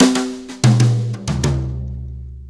Brushes Fill 69-07.wav